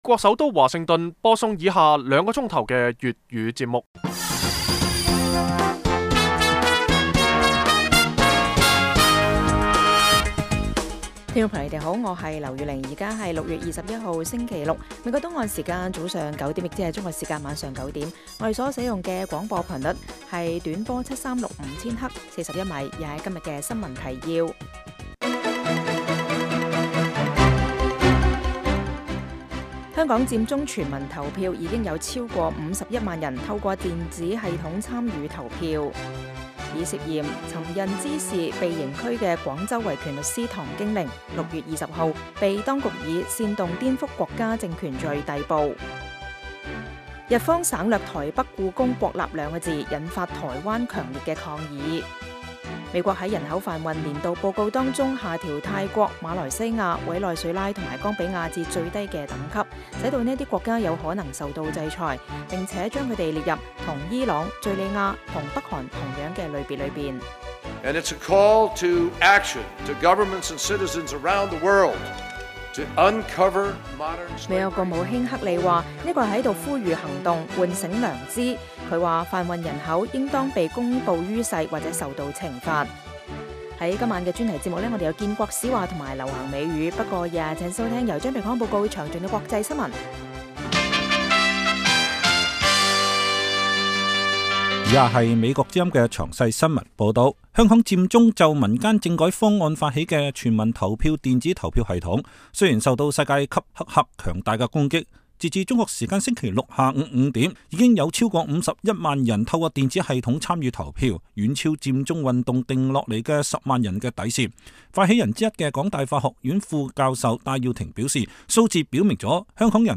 每晚 9點至10點 (1300-1400 UTC)粵語廣播，內容包括簡要新聞、記者報導和簡短專題。